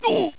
GRUNT3.WAV